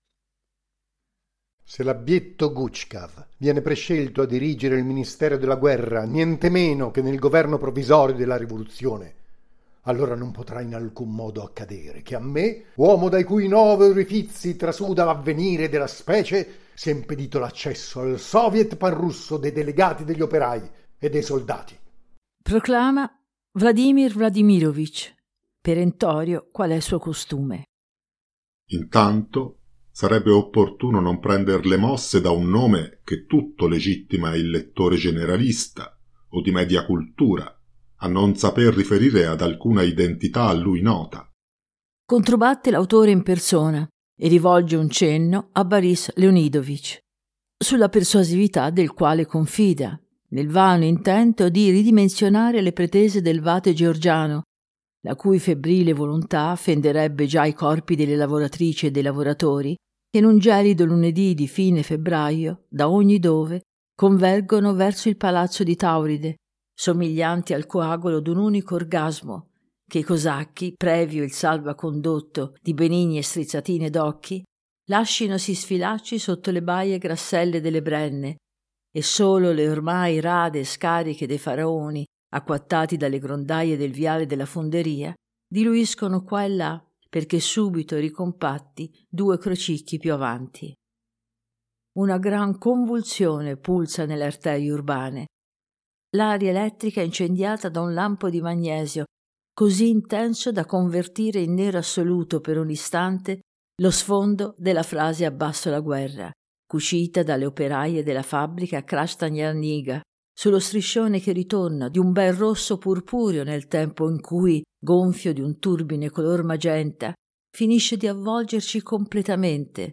trailer_Il-capolavoro-di-Prassede.mp3